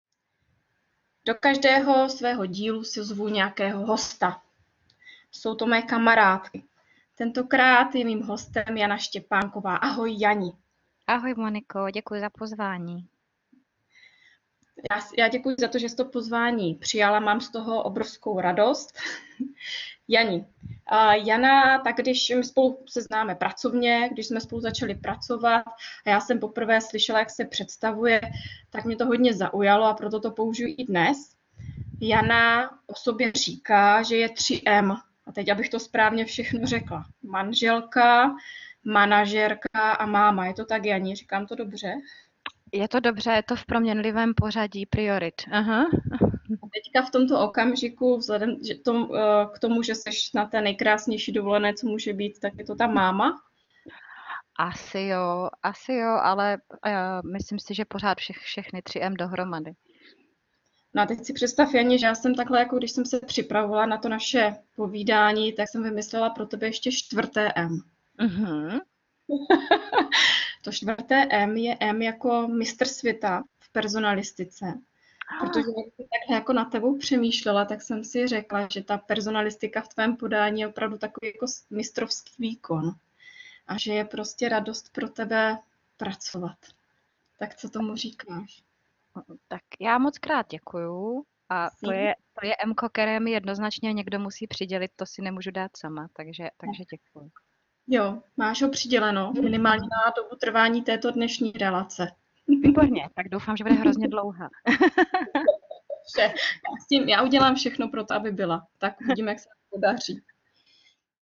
Můj hlas už nezní po celou dobu tak nesměle a nervózně.